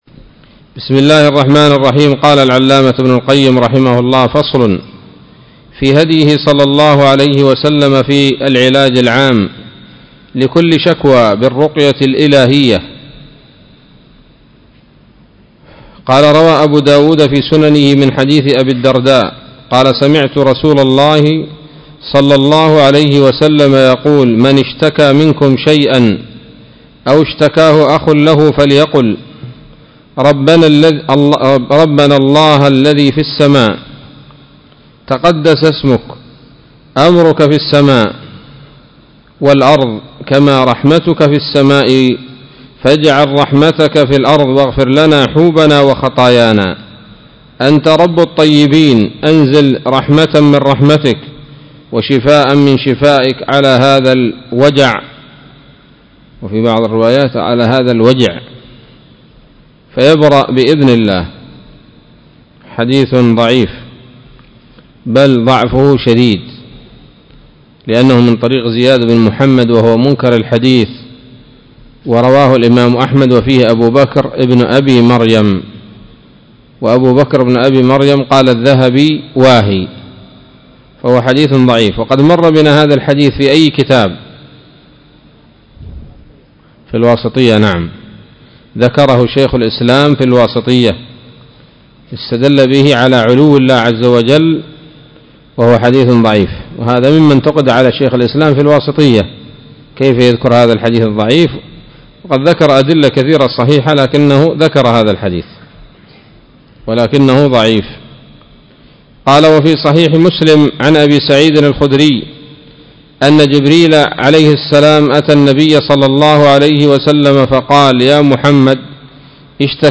الدرس التاسع والأربعون من كتاب الطب النبوي لابن القيم